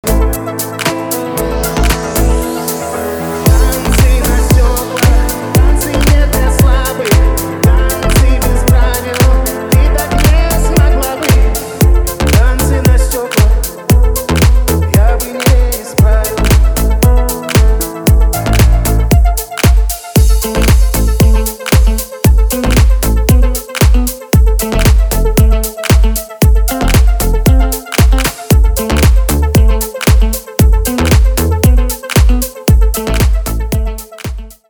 • Качество: 320, Stereo
deep house